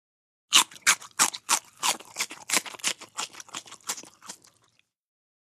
BiteCeleryChew PE677904
DINING - KITCHENS & EATING CELERY: INT: Bite into celery & chew.